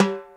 TSW TIMP 1.wav